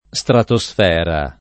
[ S trato S f $ ra ]